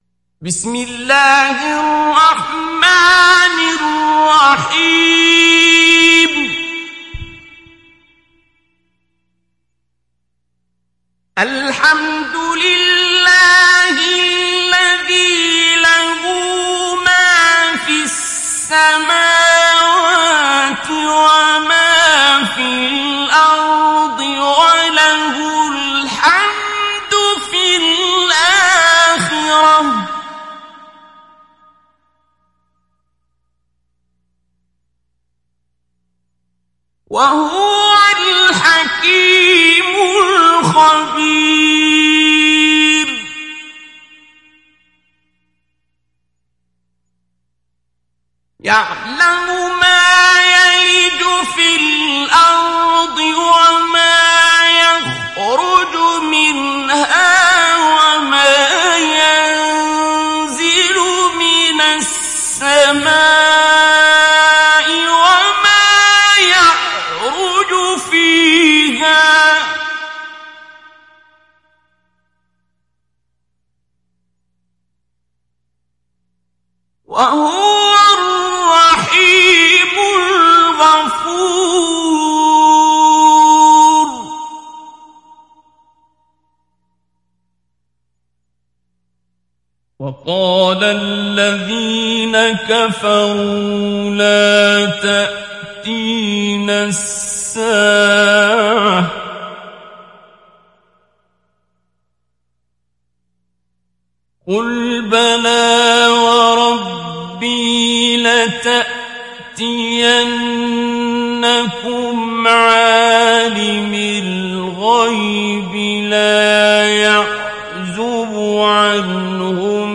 Download Surat Saba Abdul Basit Abd Alsamad Mujawwad